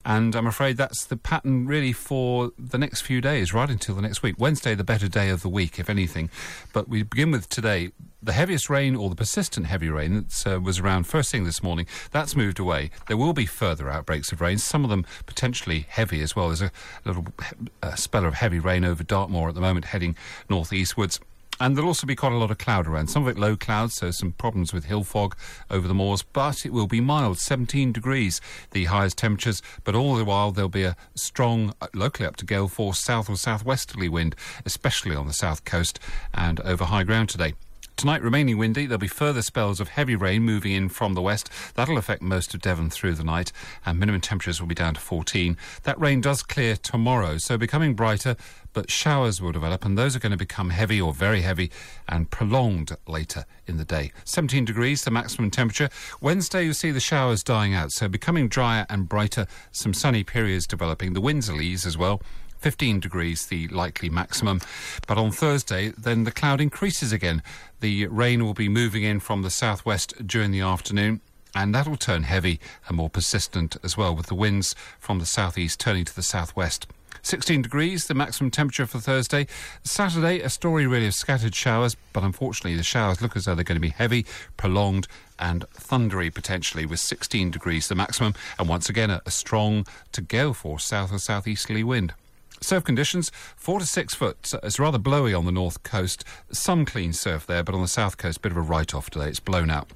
5 day forecast for Devon from 8.35AM on 21 October